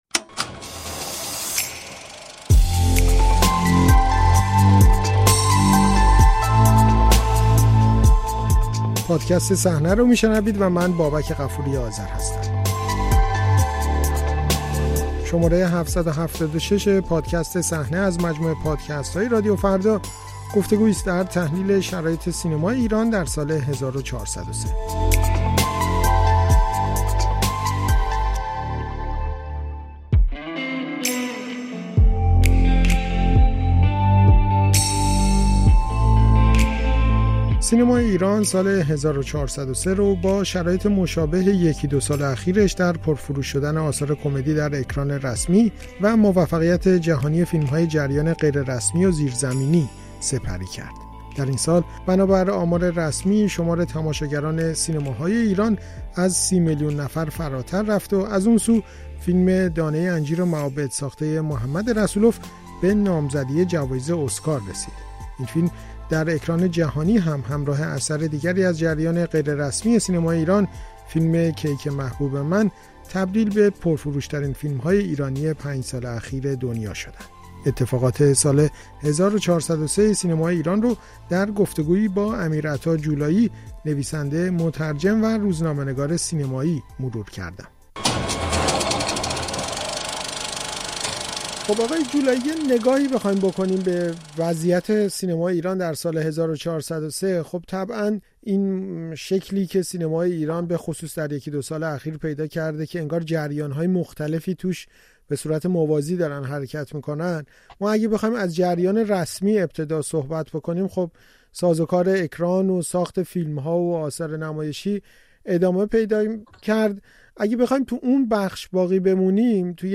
گفت وگویی